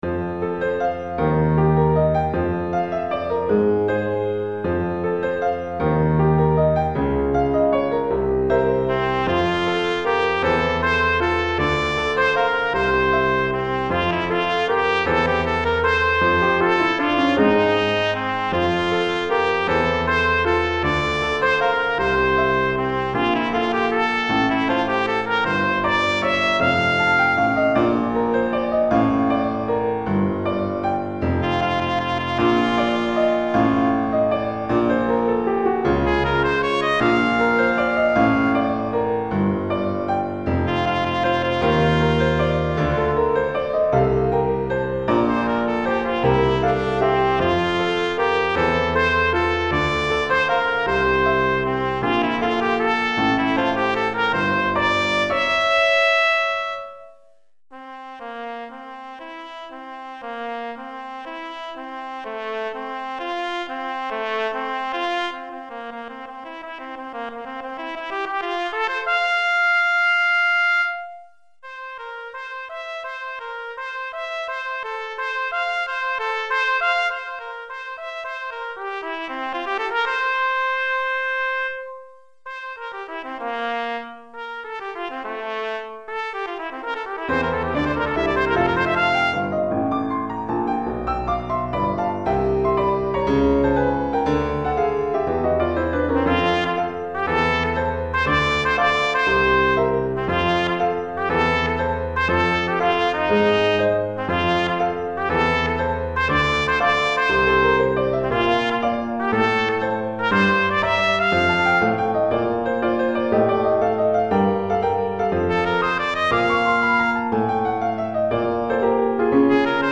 Trompette en Sib et Piano